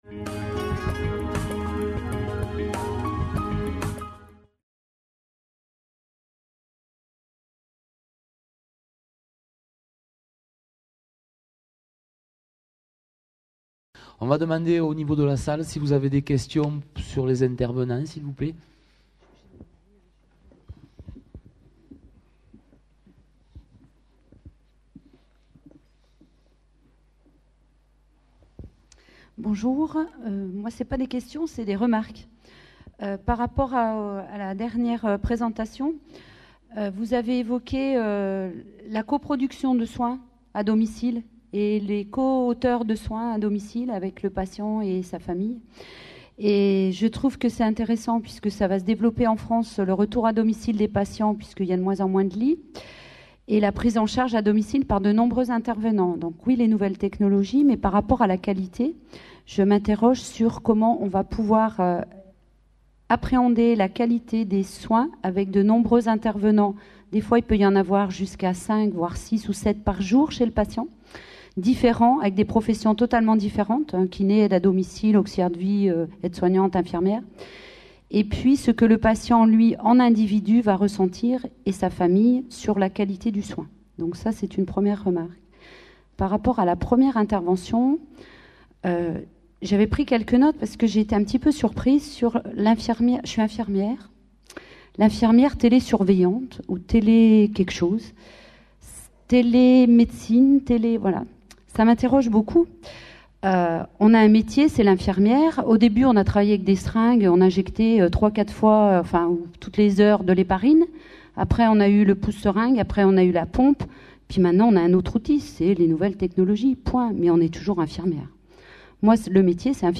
Conférence enregistrée lors du congrès international FORMATIC PARIS 2011. Atelier TIC et pratiques innovantes au service de la formation des professionnels de la santé.